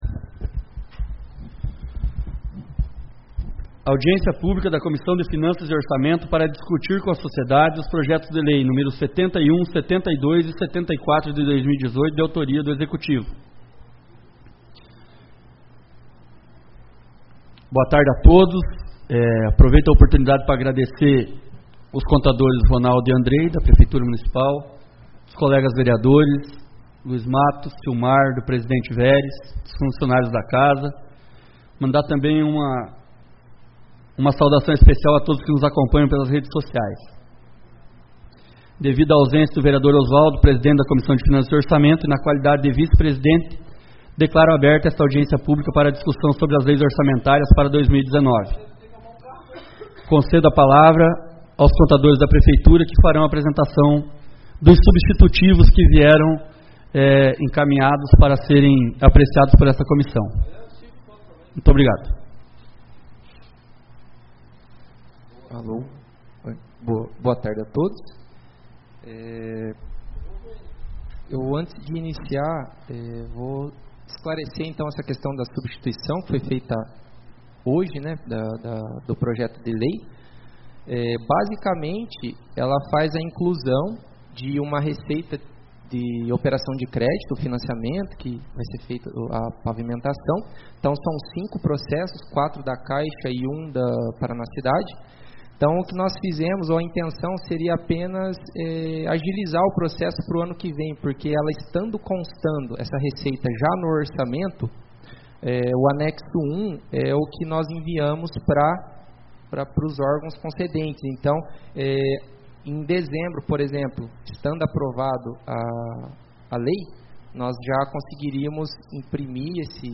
2018-11-22 - Audiência Pública da LOA 2019.mp3